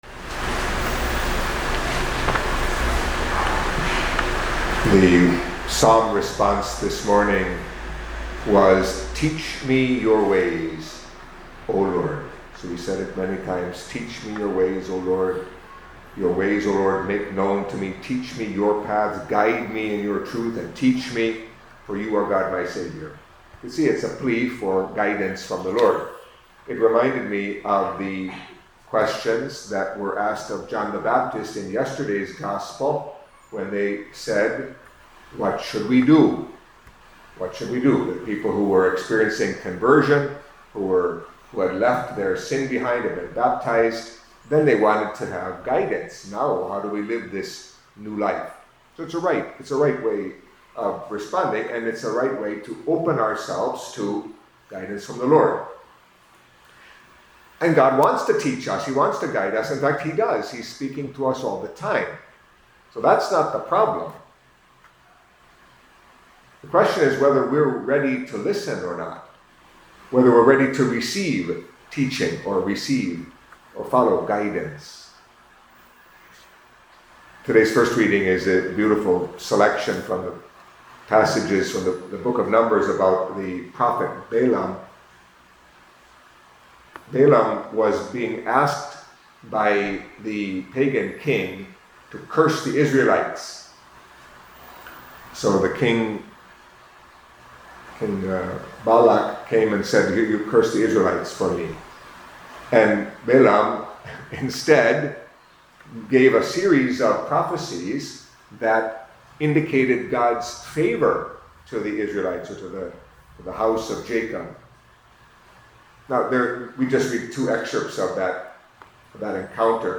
Catholic Mass homily for Monday of the Third Week of Advent